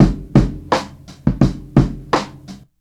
Streetrim 85bpm.wav